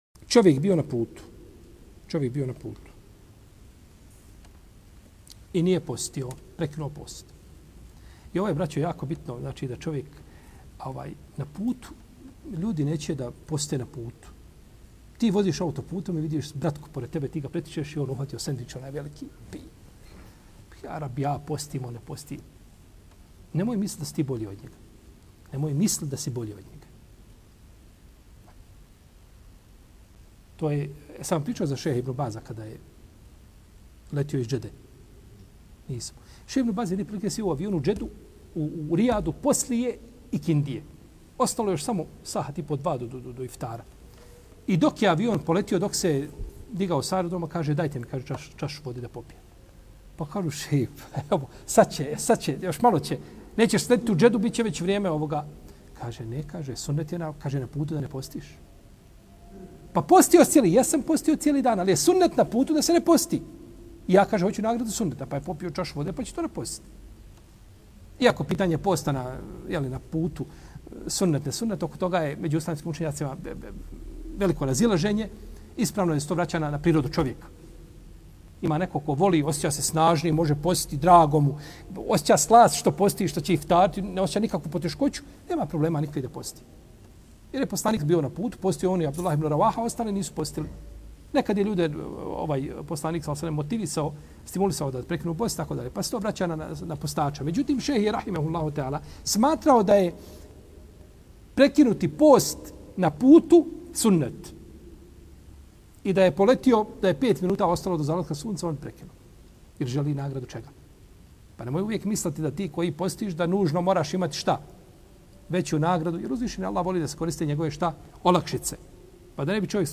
u kratkom predavanju